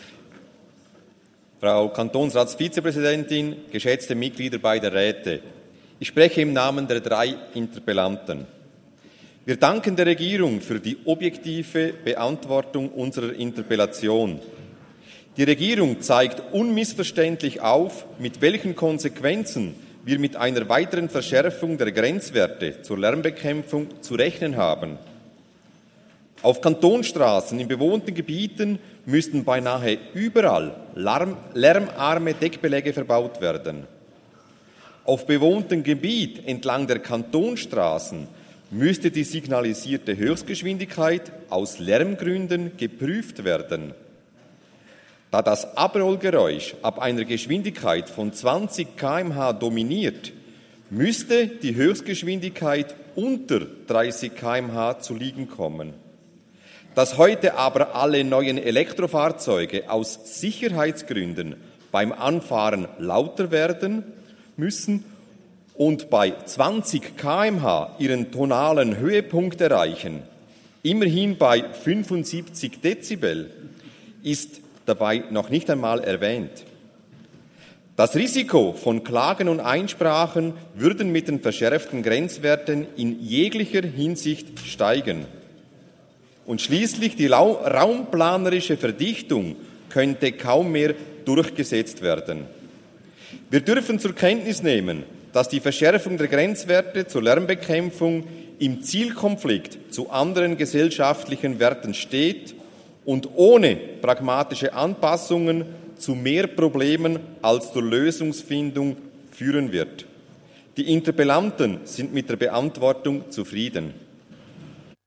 20.9.2023Wortmeldung
Session des Kantonsrates vom 18. bis 20. September 2023, Herbstsession